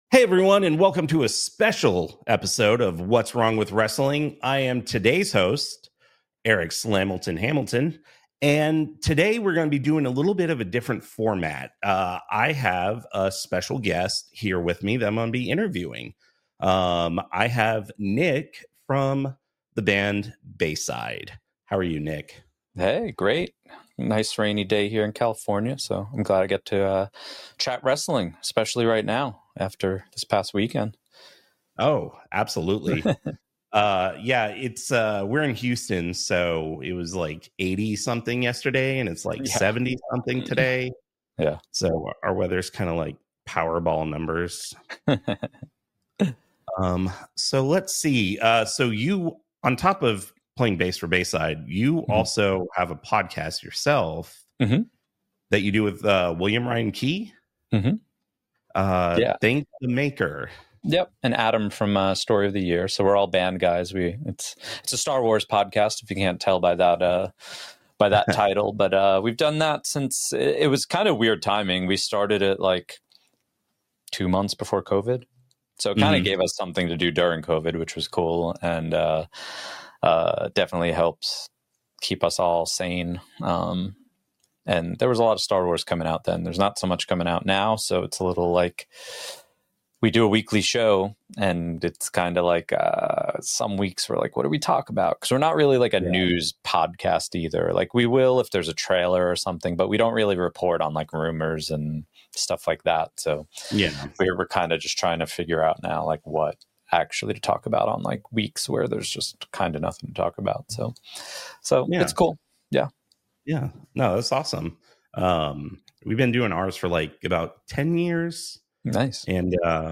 WWE Recap Show